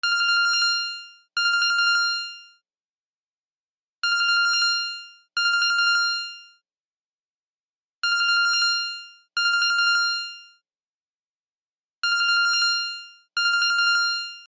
携帯着信音
カラララン